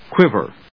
/kwívɚ(米国英語), kwívə(英国英語)/